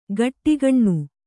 ♪ gaṭṭigaṇṇu